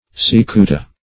Cicuta \Ci*cu"ta\, n. [L., the poison hemlock.]